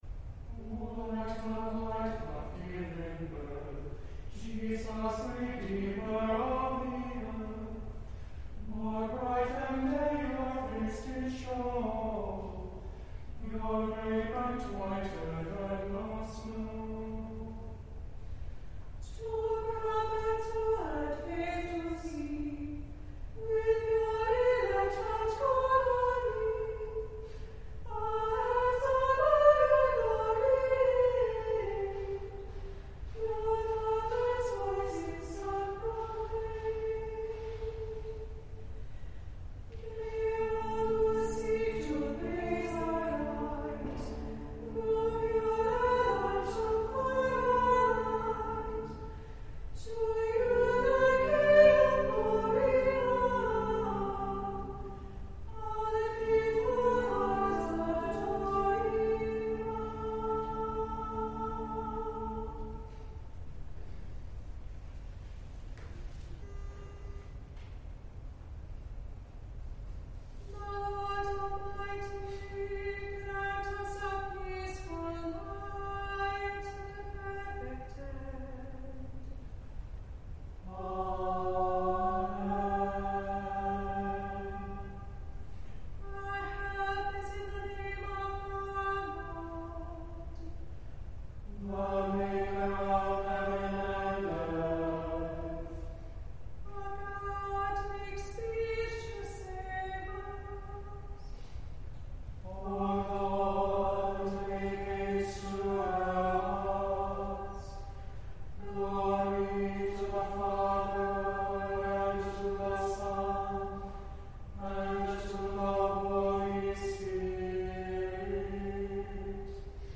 On Sundays, virtual and in-person services of prayers, scripture, and a sermon are at 8 a.m., 10:30 a.m., and 6 p.m., and a sung service of Compline begins at 7 p.m.
Christ Church Audio Home Categories Admin Compline 2025-03-02 The Compline Choir Download Size: 9 MB 1 Powered by Podcast Generator , an open source podcast publishing solution | Theme based on Bootstrap